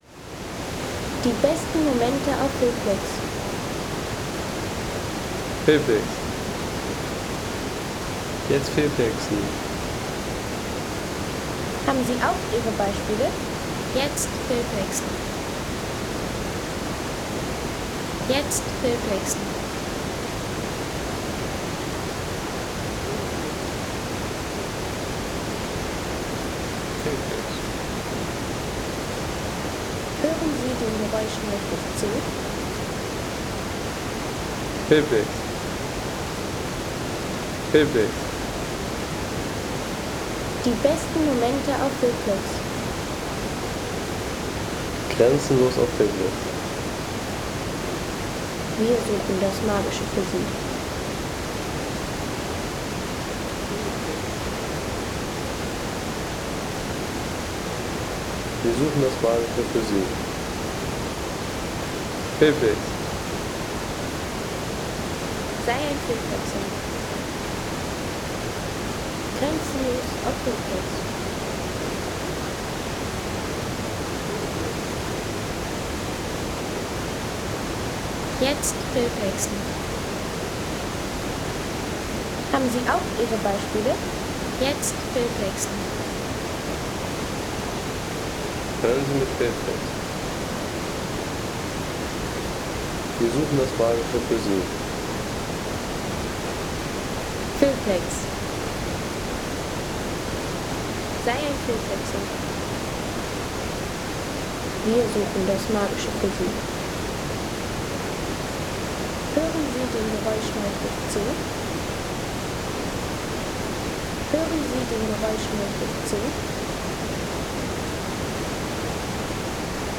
Talbach - Schladming Home Sounds Landschaft Bäche/Seen Talbach - Schladming Seien Sie der Erste, der dieses Produkt bewertet Artikelnummer: 201 Kategorien: Landschaft - Bäche/Seen Talbach - Schladming Lade Sound.... Wanderweg 'Wilde Wasser' – Talbach und Natur in Schladming.